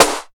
74 SNARE 2.wav